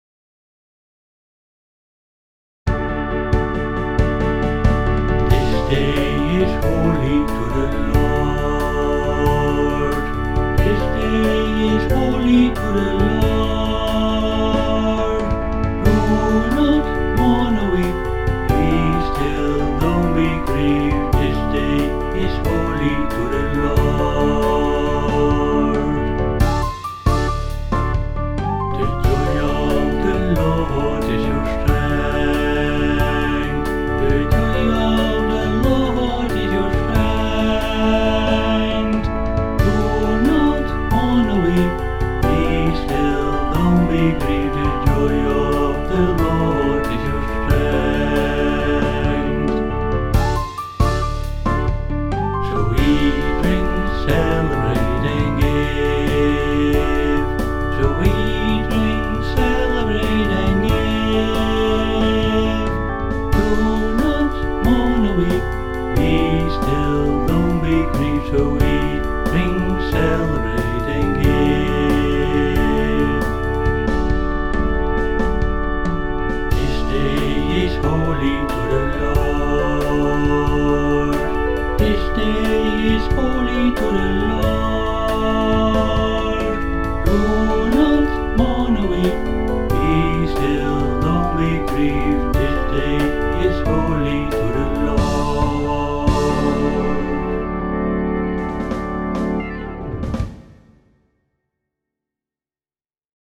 (blues)